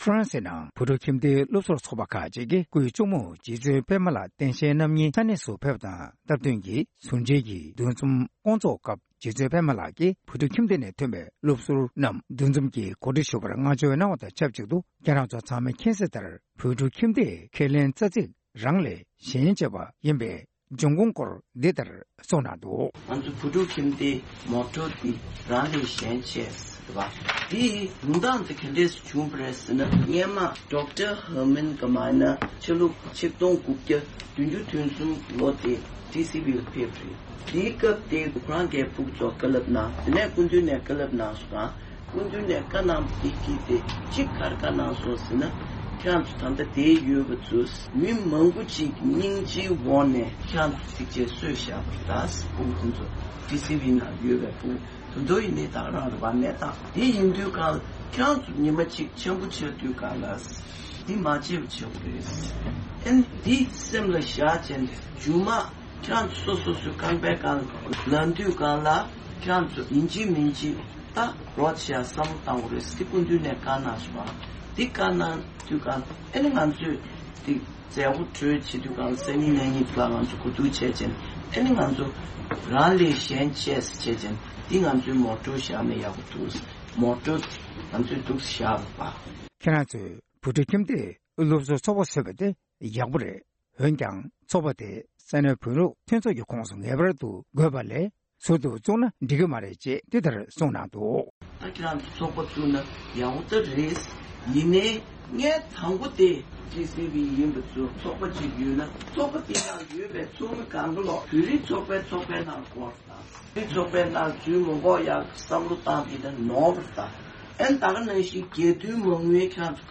སྒྲ་ལྡན་གསར་འགྱུར།
༄༅། ། ཕ་རནྶེ་ནང་བོད་ཕྲུག་ཁྱིམ་སྡེའི་སློབ་ཟུར་ཚོགས་པས་སྐུའི་གཅུང་མོ་རྗེ་བཙུནཔདྨ་ལགས་སྟེང་ཞལ་རྣམ་གཉིས་ཀྱི་ཟུར་མཇལ་འདུ་འཛོམས་ཐོག་གསུང་བཤད་གནང་བའི་སྐབས།